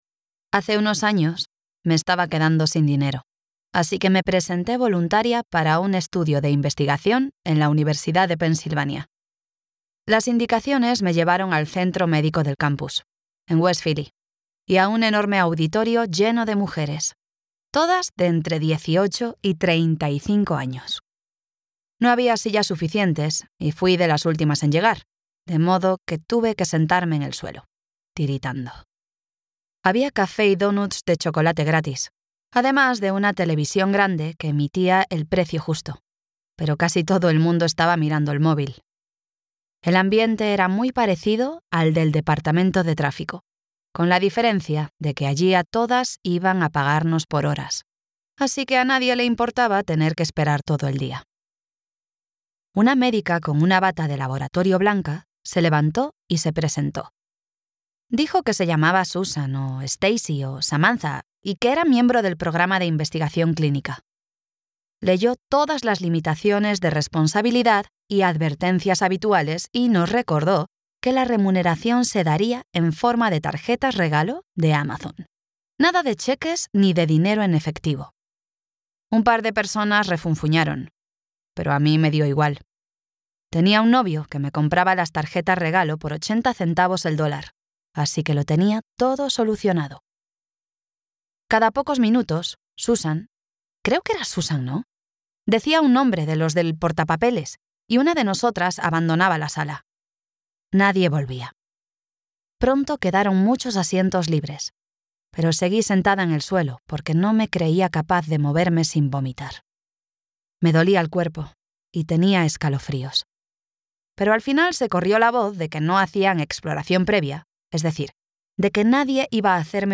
Audiolibro Figuras ocultas (Hidden Pictures)